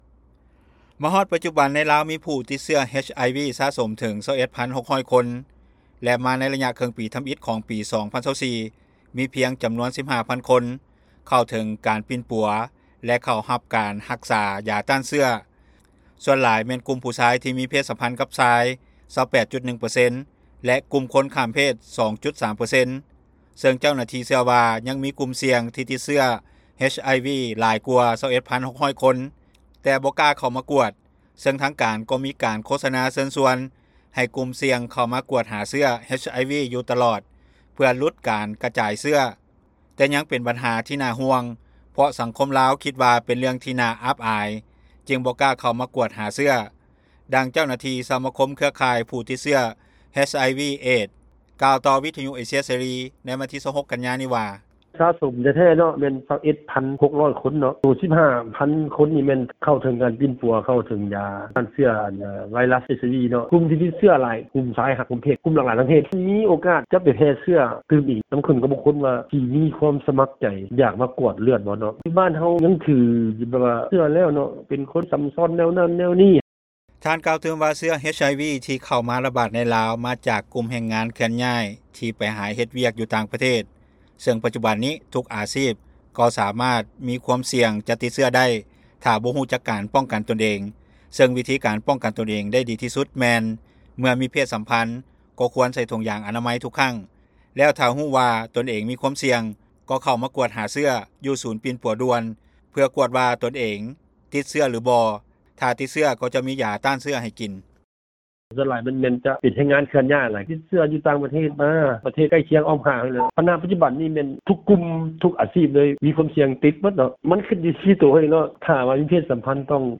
ກ່ຽວກັບເລື່ອງນີ້ຊາວໜຸ່ມທ່ານໜຶ່ງ ກໍ່ຮູ້ສຶຢ້ານແຕ່ກໍ່ຮູ້ວິທີປ້ອງກັນ ເພາະສາເຫດທີ່ຕິດເຊື້ອ HIV ສ່ວນໃຫຍ່ມາຈາກການມີເພດສຳພັນ ກັບຄົນອື່ນ ໂດຍບໍ່ປ້ອງກັນໃສ່ຖົງຢາງອະນາໄມ ເຊິ່ງຖ້າມີຄົນໃກ້ຊິດ ມີຄວາມສ່ຽງຈະຕິດເຊື້ອ ກໍ່ຈະແນະນໍາໃຫ້ເຂົາເຈົ້າໄປໂຮງໝໍ ແລະຖ້າຕ້ອງຢູ່ກັບຄົນຕິດເຊື້ອ ກໍ່ບໍ່ໃຫ້ລັງກຽດເຂົາເຈົ້າ ພຽງແຕ່ຂອງໃຊ້ບາງຢ່າງກໍ່ຕ້ອງແຍກກັນໃຊ້ ບໍ່ຮ່ວມກັບເຂົາເຈົ້າ.
ພ້ອມດຽວກັນນນີ້ໄວໜຸ່ມຍິງນາງໜຶ່ງ ກໍ່ກ່າວວ່າກໍ່ຮູ້ສຶກຢ້ານຢູ່ຖ້າສົມມຸດແຖນເປັນຄົນເຈົ້າຊູ່ ມັກໄປທ່ຽວສາວບໍລິການ ກໍ່ມີຄວາມສ່ຽງທີ່ຕິດເຊື້ອ HIV ໄດ້ເຊິ່ງໂຕເຮົາເອງ ກໍ່ຕ້ອງເບິ່ງແລະປ້ອງກັນເອົາເອງເພື່ອຈະບໍ່ໄດ້ຕິດເຊື້ອ.